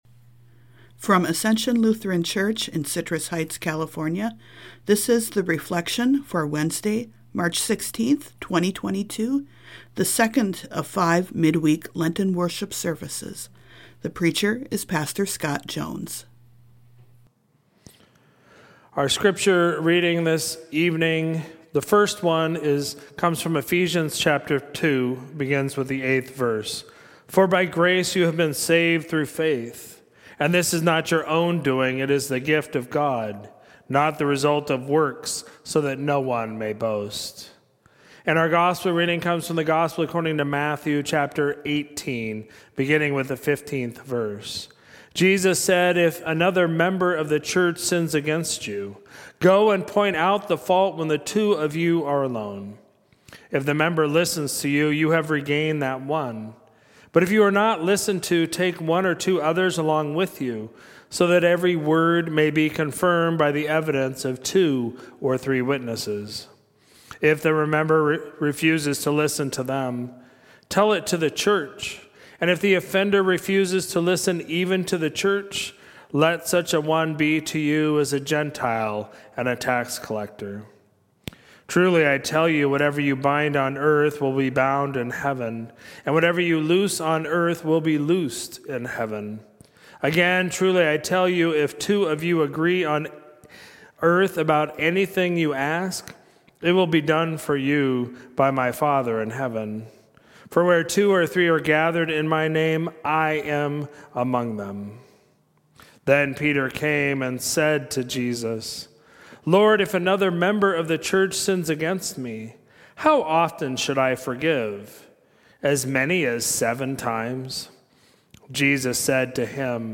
Sermons at Ascension